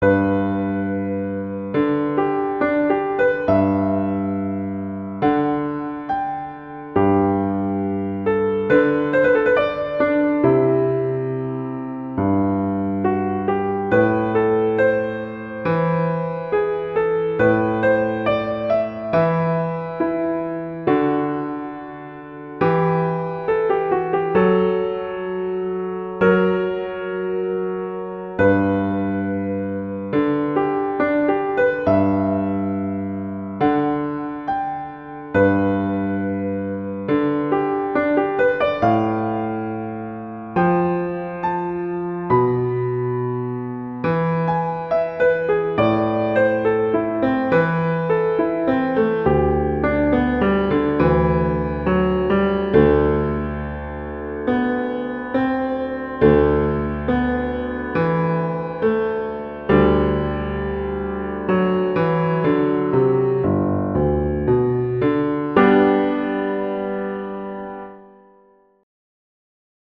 classical, wedding, french, festival, love, children
G major
♩=69 BPM